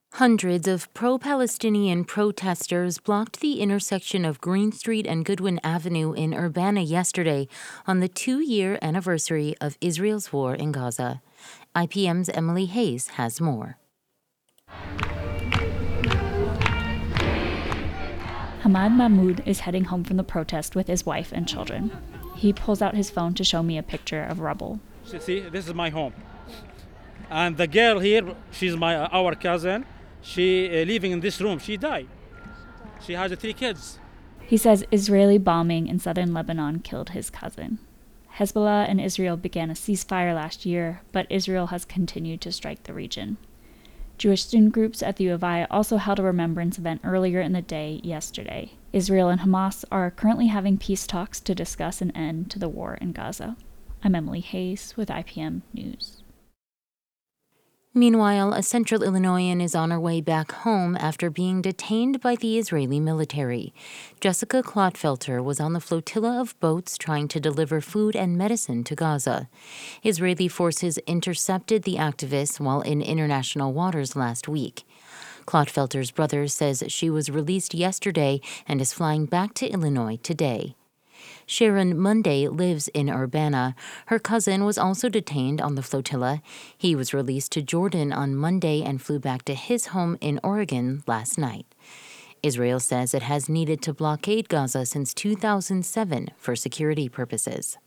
Oct-7-2025-protest-audio.mp3